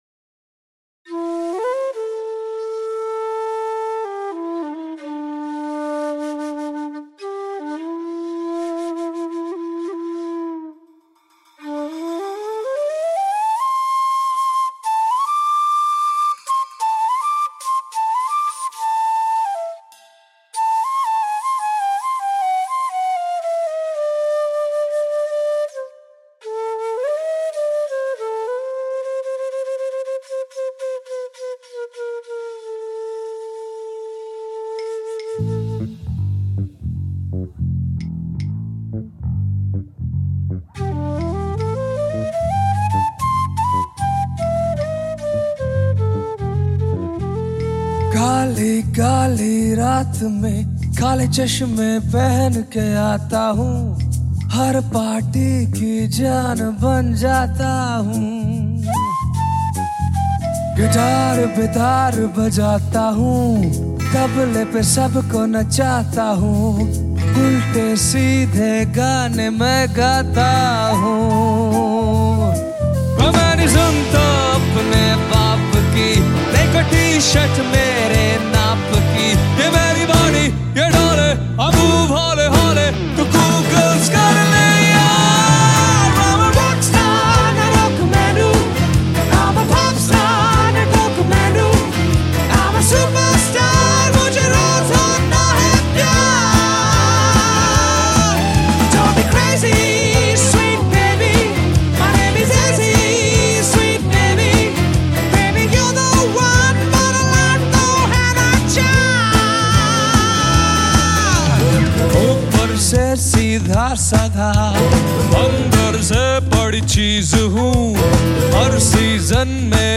Harmonium
Flute
Houseband Complete
Backing Vocalist Yes
is a funky, jazz-esque biopic
an addictive groove
high-pitched and oft baritone vocals